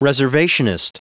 Prononciation du mot reservationist en anglais (fichier audio)
Prononciation du mot : reservationist